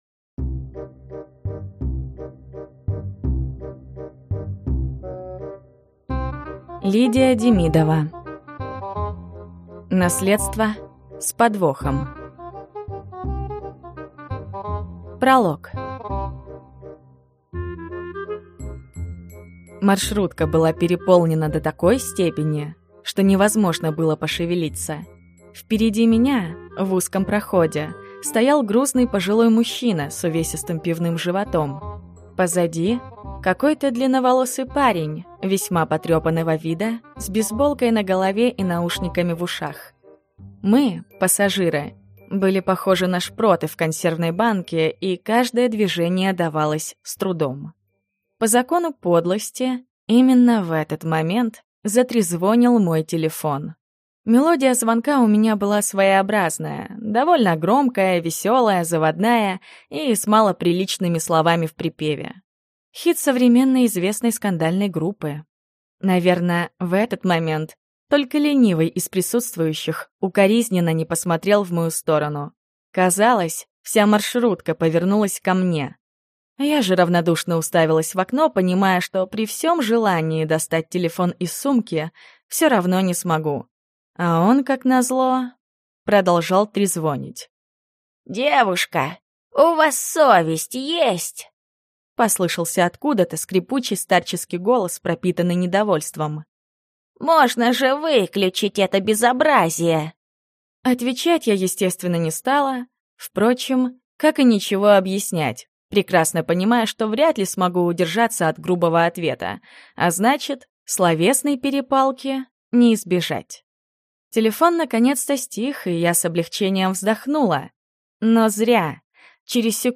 Аудиокнига Наследство с подвохом | Библиотека аудиокниг